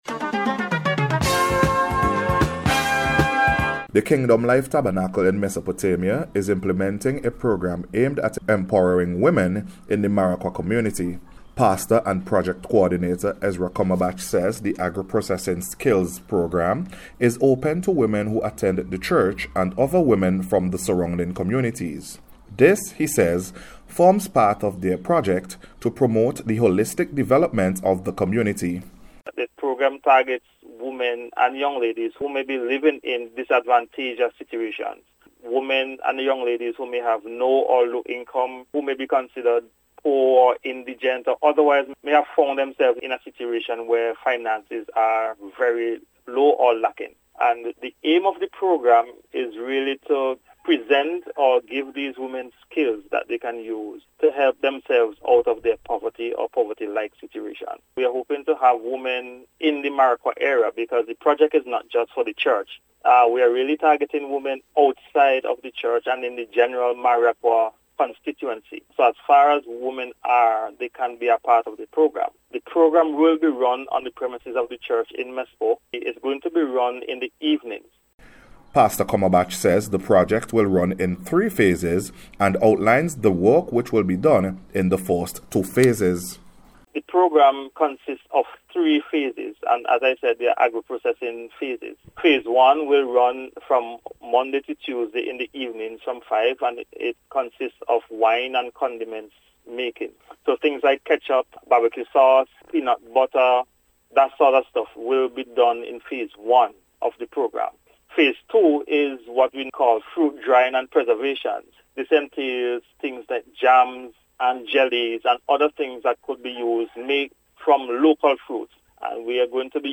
NBC Radio Special Report – Monday February 20th 2023